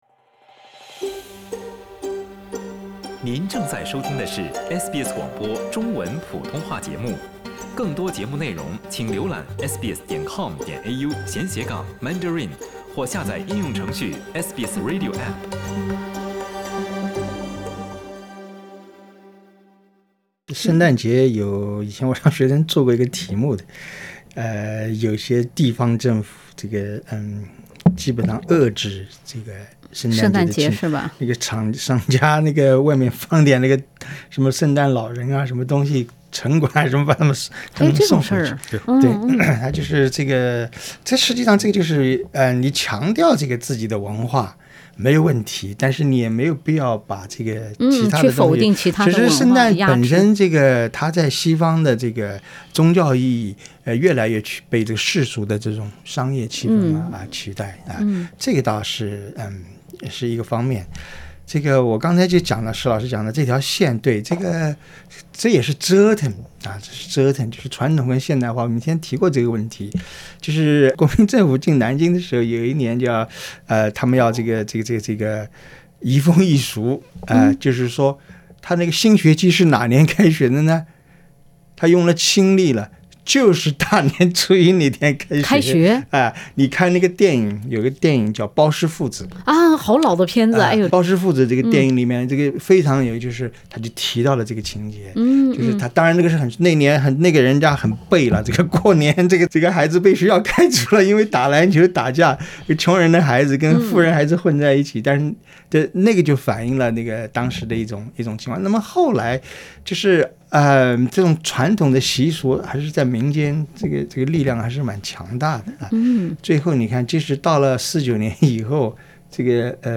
欢迎收听SBS 文化时评栏目《文化苦丁茶》，本期话题是： 年不仅仅代表吃喝玩乐。